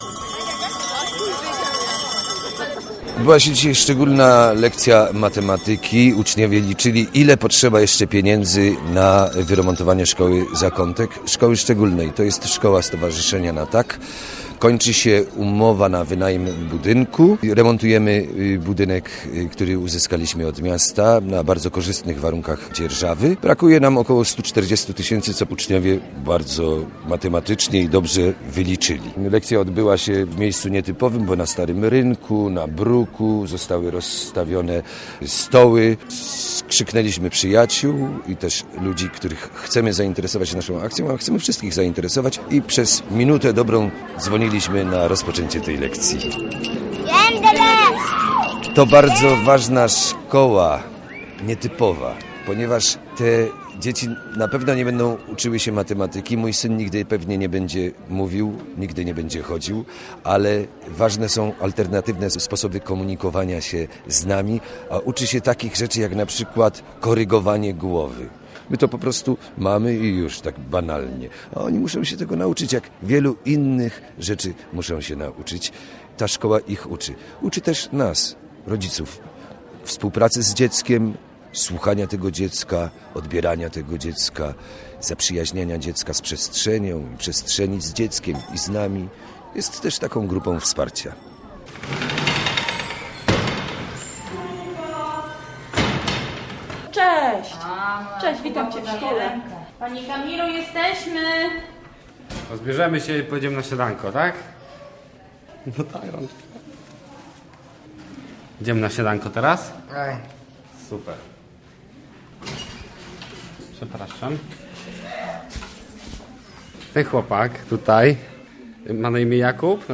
Pomóż Zakątkowi - reportaż